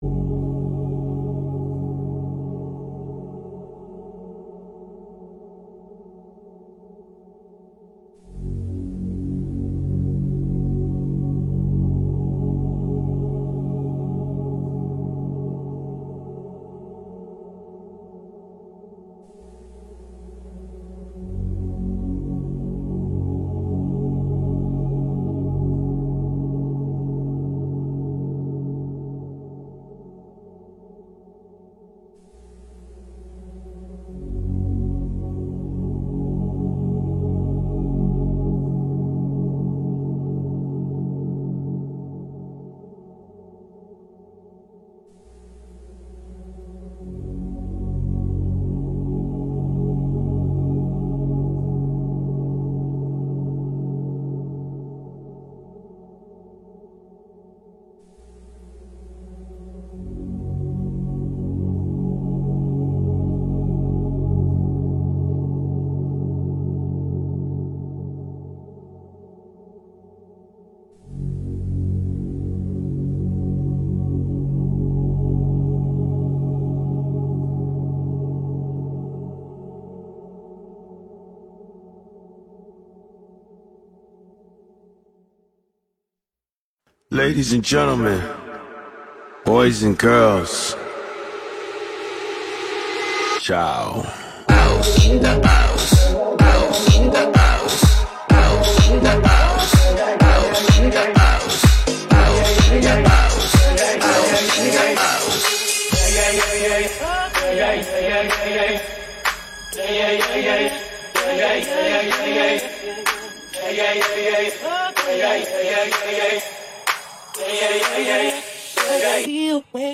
Liveset
Genre: House